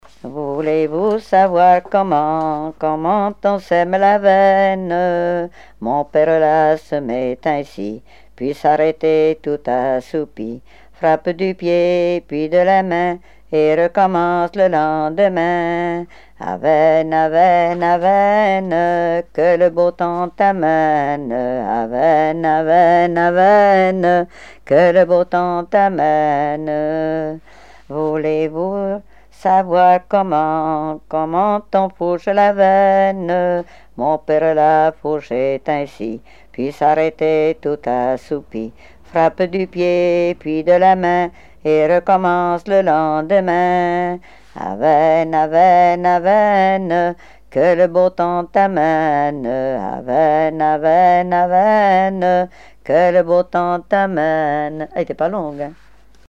Genre énumérative
Répertoire de chansons traditionnelles et populaires
Pièce musicale inédite